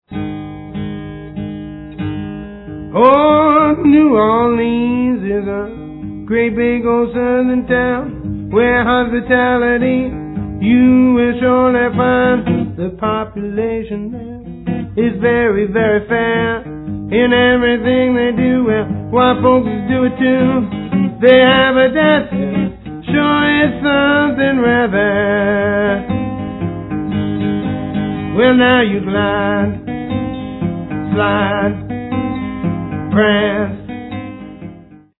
Most of the tracks are solo